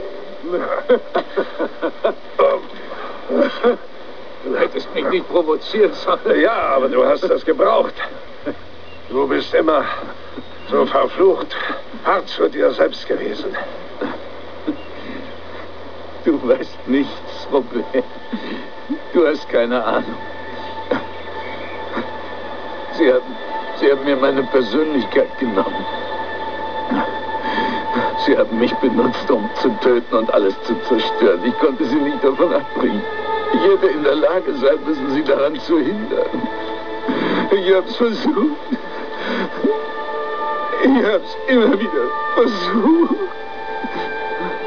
Downloads: Dialoge aus Star Trek